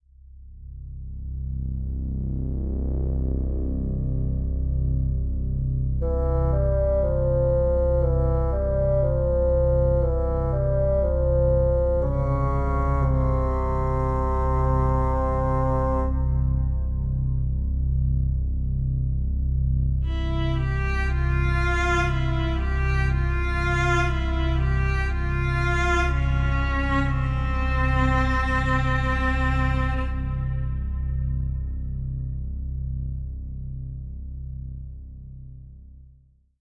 atmosphere bassoon blown cinematic dark deep drone film sound effect free sound royalty free Movies & TV